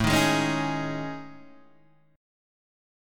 A Major 7th Flat 5th